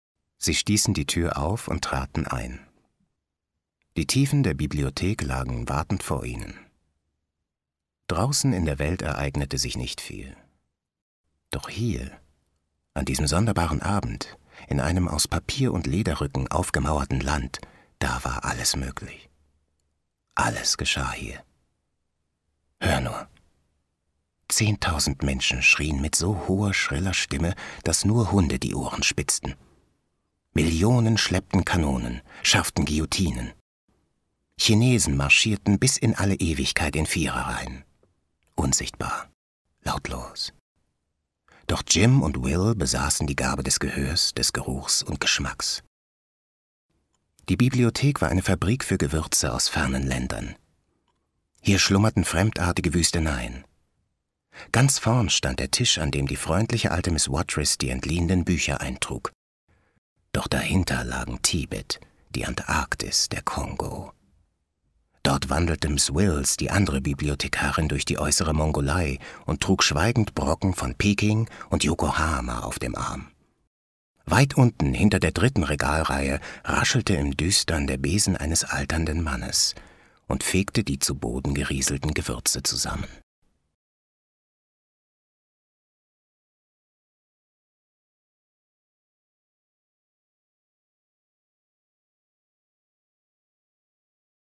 Erzählung: Ray Bradbury – Das Böse kommt auf leisen Sohlen